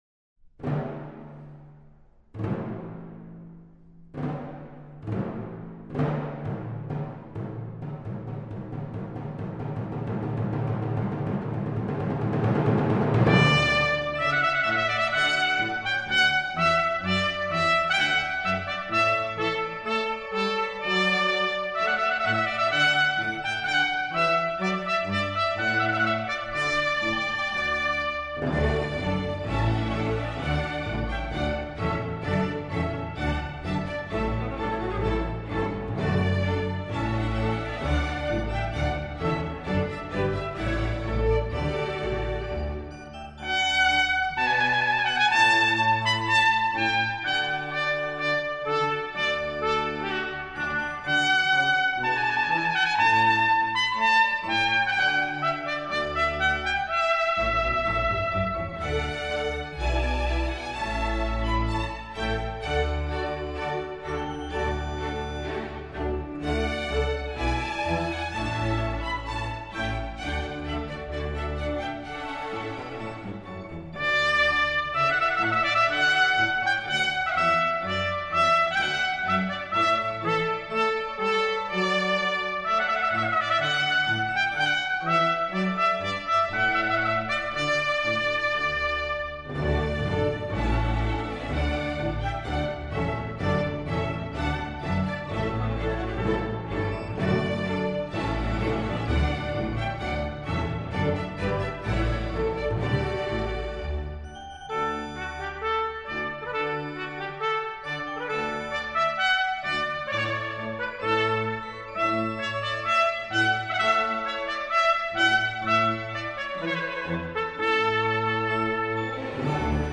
Marches